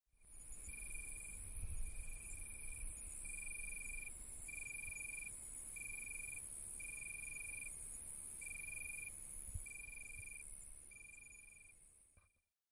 Night Summer Sound Button - Free Download & Play